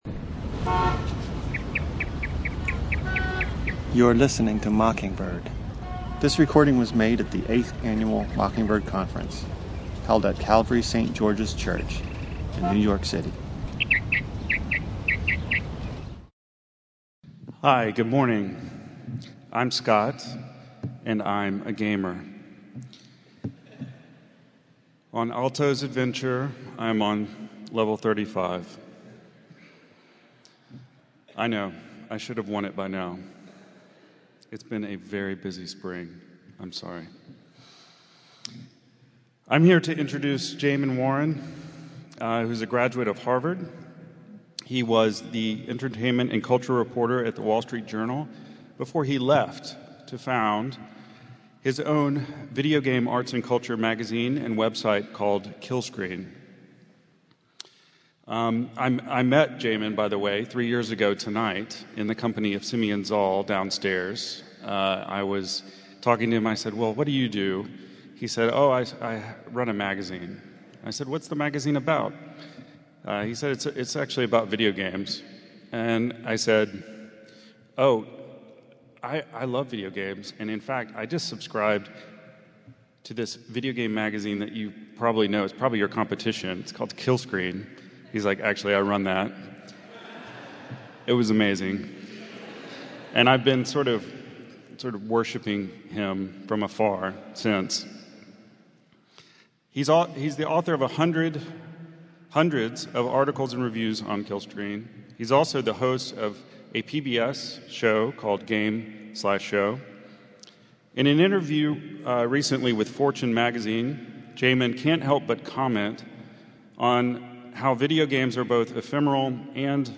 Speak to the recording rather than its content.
Venue: 2015 NYC Mockingbird Conference